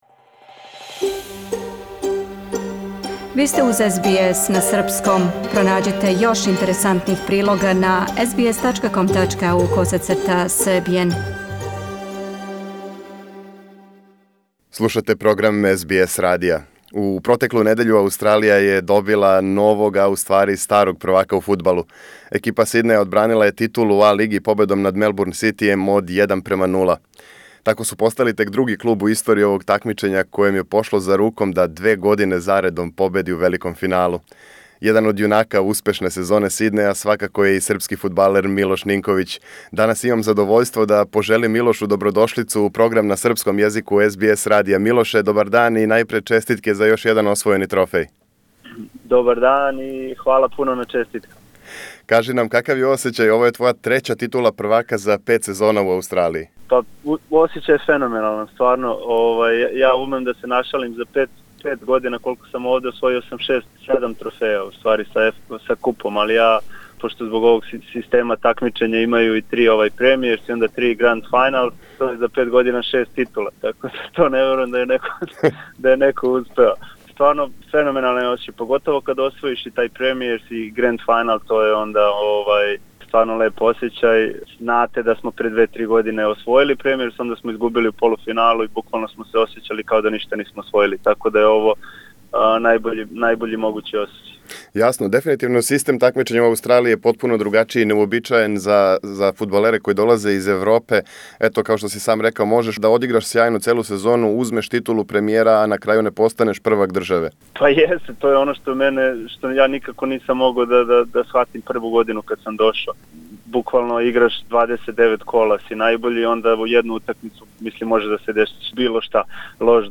Српски фудбалски ас говори за СБС радио о још једној шампионској сезони и седам освојених пехара са екипом Сиднеја, о животу у Аустралији, Црвеној звезди, плановима за будућност, али и како му је пандемија помогла да коначно проведе више времена с породицом.